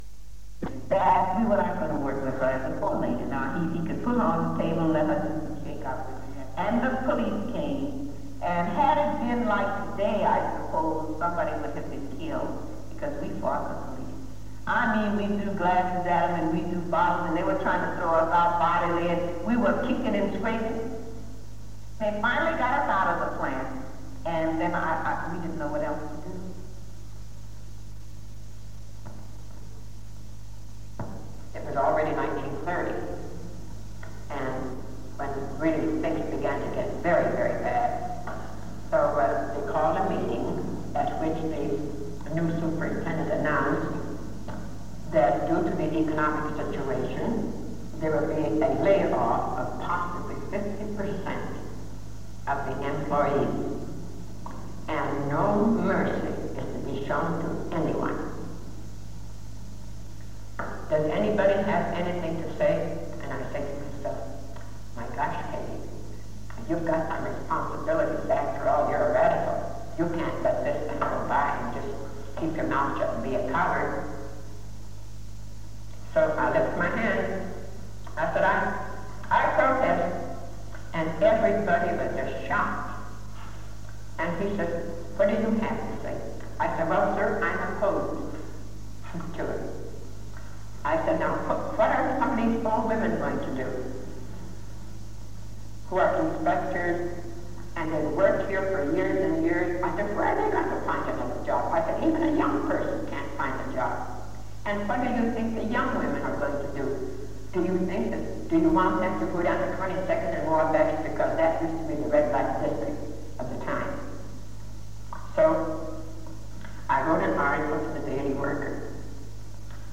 Det Norske Studentersamfund, Generalforsamling, 24.11.1978 (fil 1-2:6)